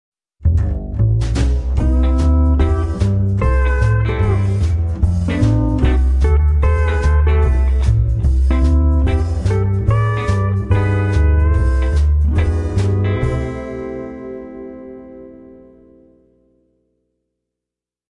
Genres: Country (9)